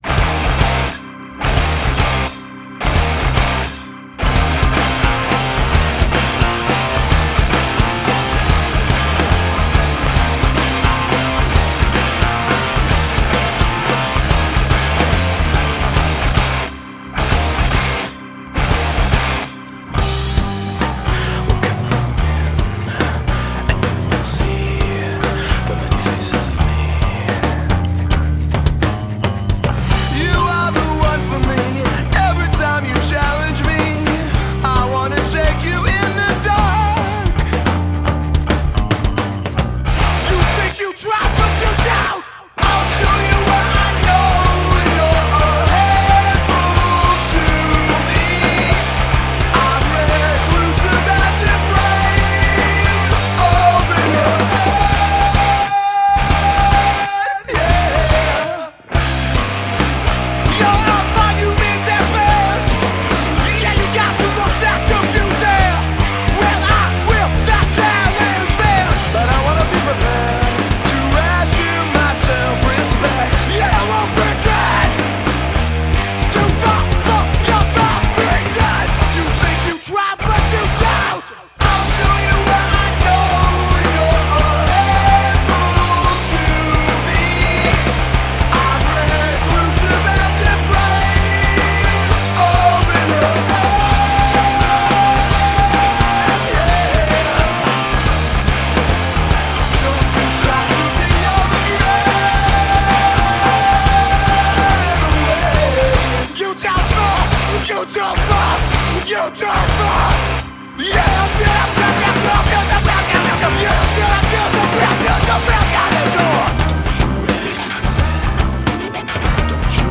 vocals, trumpet, keys
guitar
bass
drums and percussion
Tracked at Sandline Studios